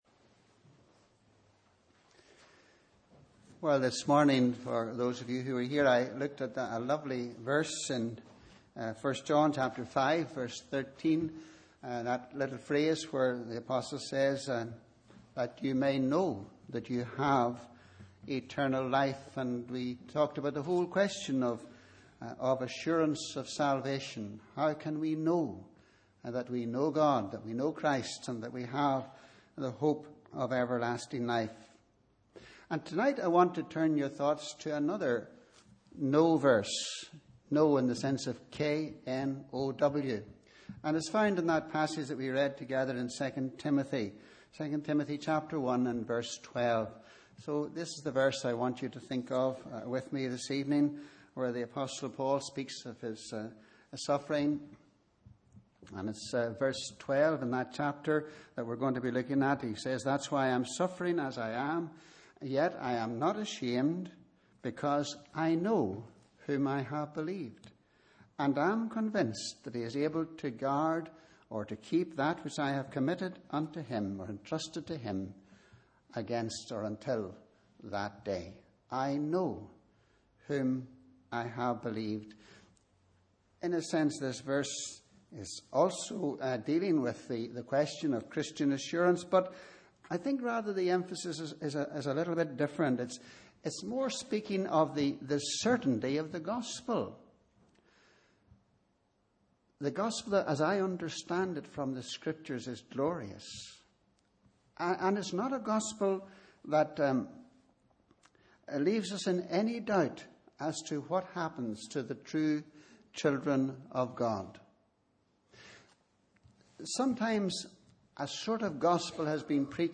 Sunday 8th May – Evening Service